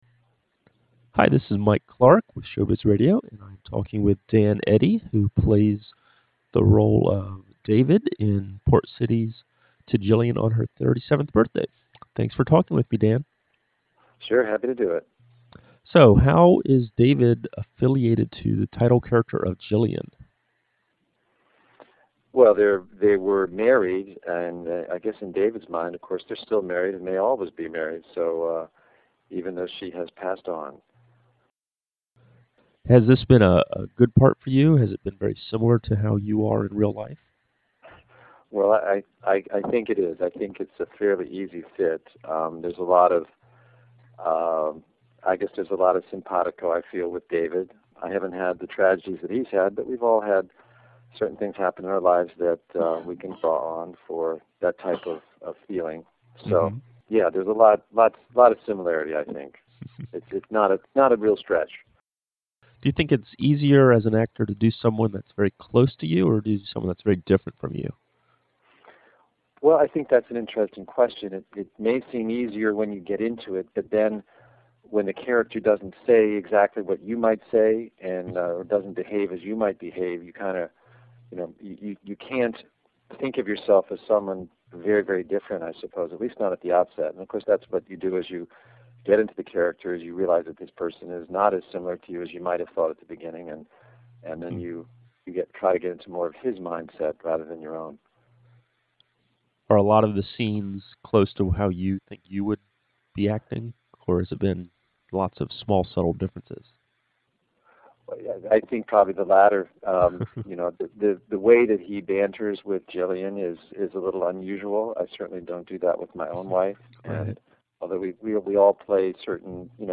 By • Oct 24th, 2007 • Category: Backstage, Interviews, To Gillian on Her 37th Birthday